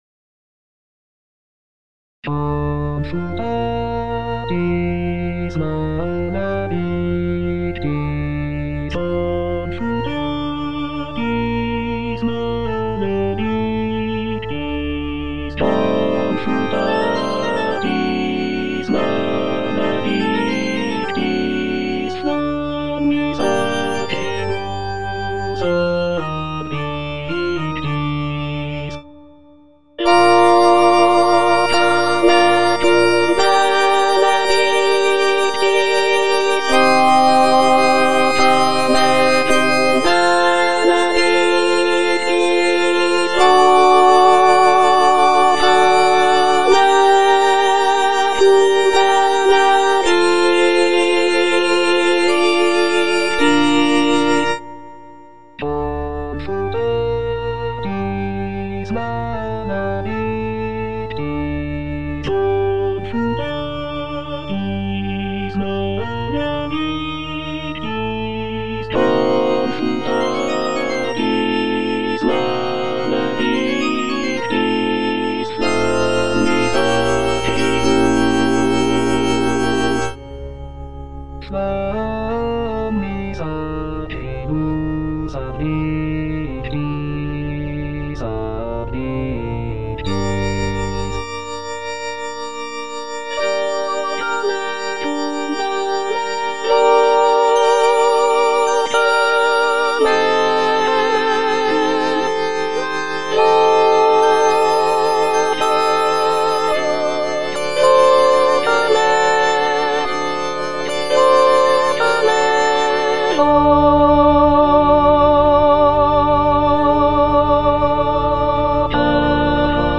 F. VON SUPPÈ - MISSA PRO DEFUNCTIS/REQUIEM Confutatis (alto I) (Emphasised voice and other voices) Ads stop: auto-stop